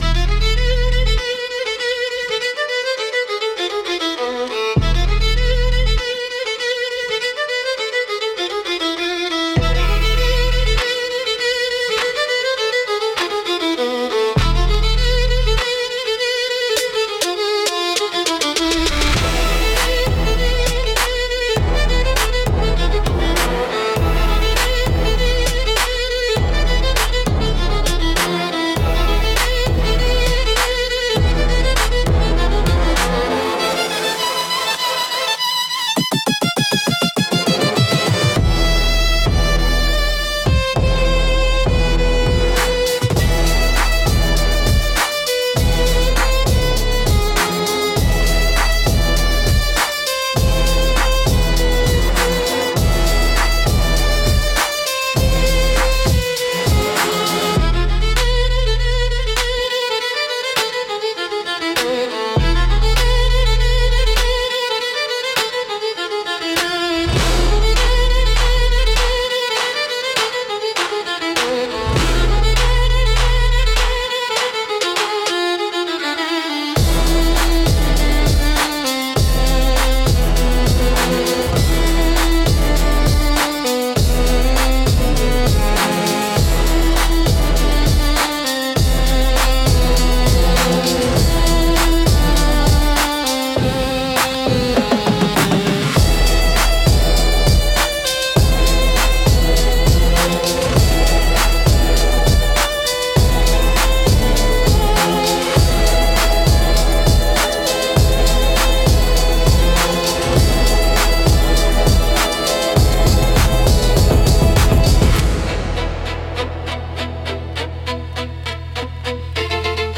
Instrumental - Heat Between the Lines